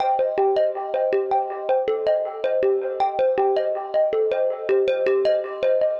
Ambient / Keys / SYNTH013_AMBNT_160_C_SC3(R).wav
1 channel